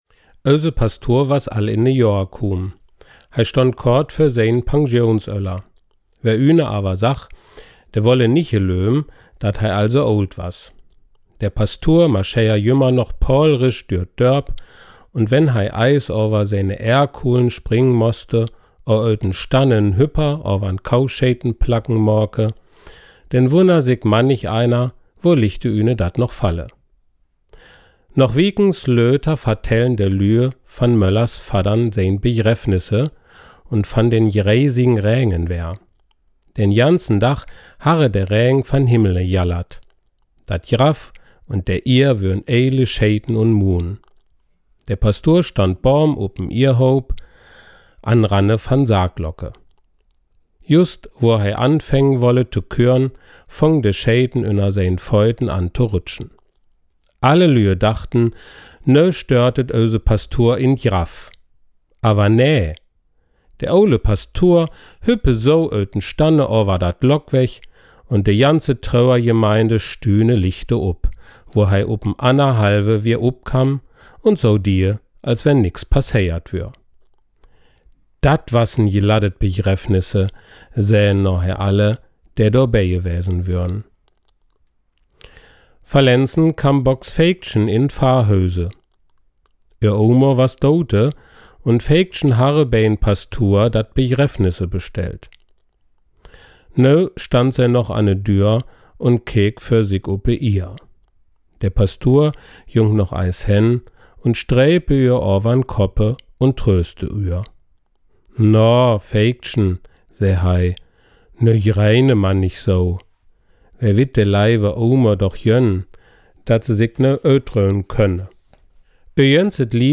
Südhorster Platt
Dat-Begräffnis-Südhorster-Platt.mp3